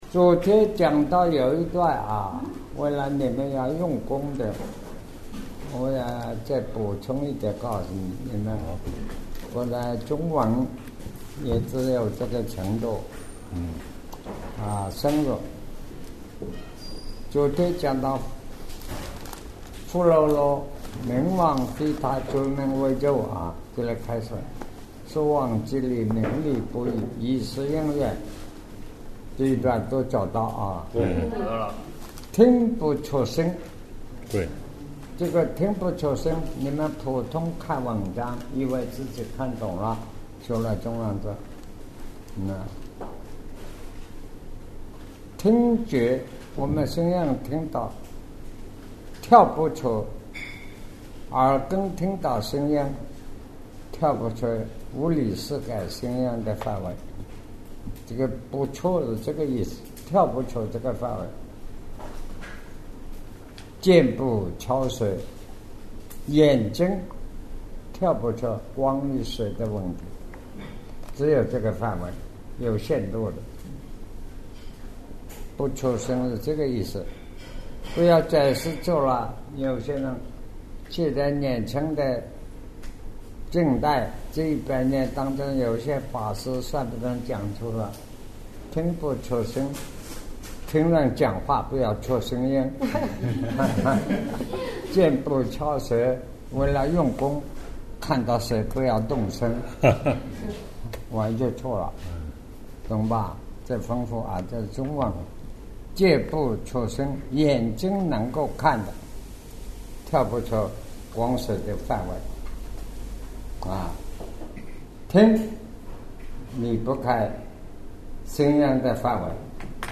南怀瑾先生讲宇宙生命的缘起